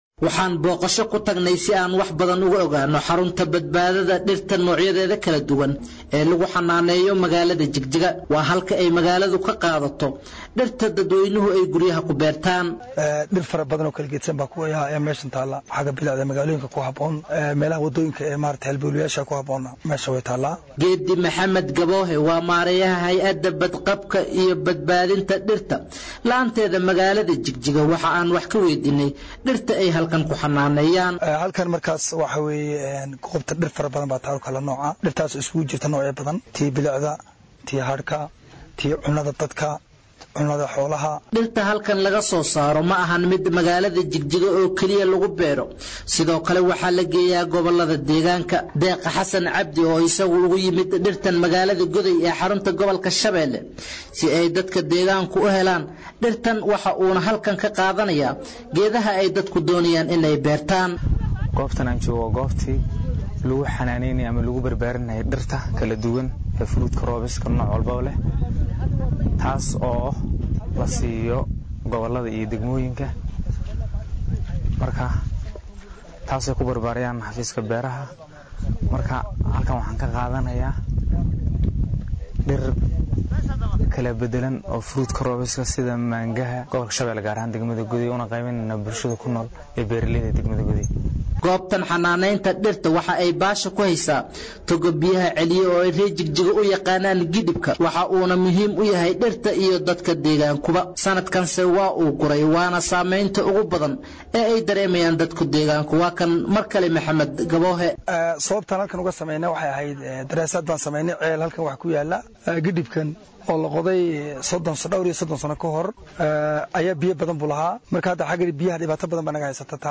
Dowlad deegaanka Soomaalida dalka Itoobiya ayaa waxaa ka socdo barnaamij ballaaran oo loogu magac daray dhir beer oo noolal beer kaasoo lagula dagaallamaya xaalufinta dhirta iyo isbeddelka cimillada. Warbixin arrintan ku saabsan